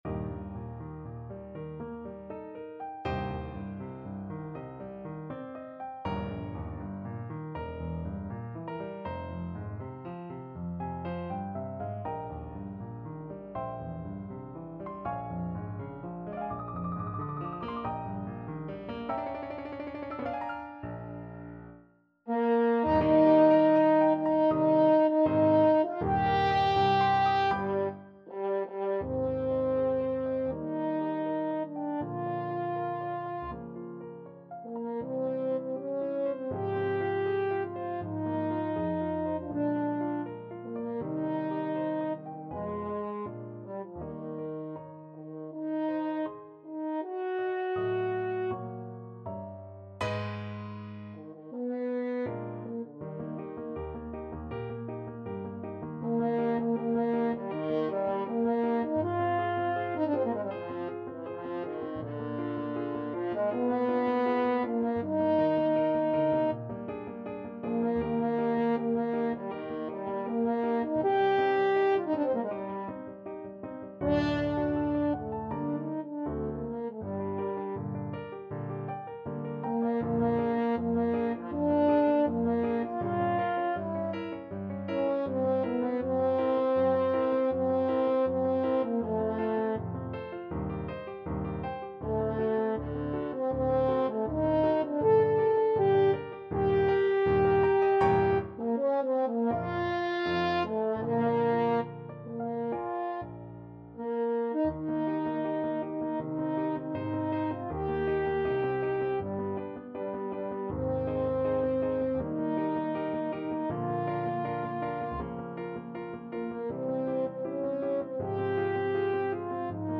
4/4 (View more 4/4 Music)
Classical (View more Classical French Horn Music)